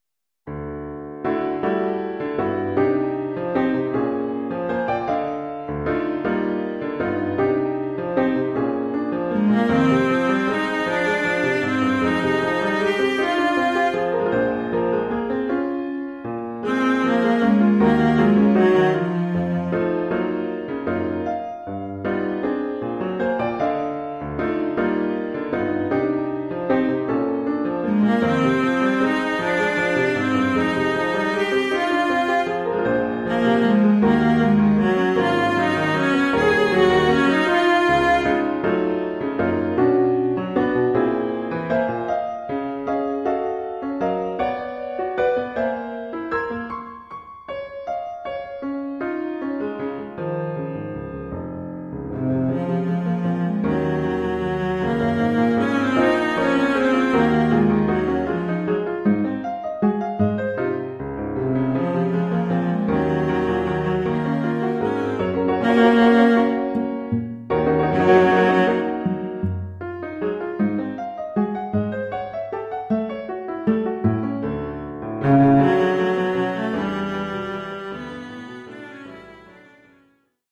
Oeuvre pour violoncelle et piano.